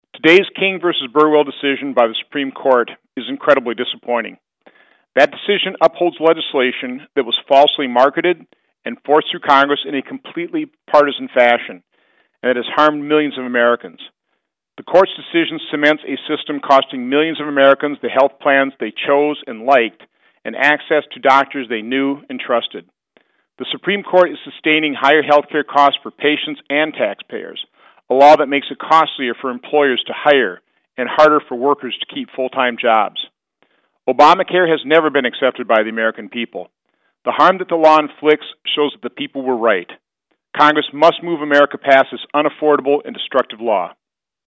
WASHINGTON — Sen. Ron Johnson (R-Wis.) made these remarks about the Supreme Court’s decision on the Affordable Care Act: